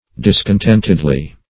-- Dis`con*tent"ed*ly, adv. -- Dis`con*tent"ed*ness, n.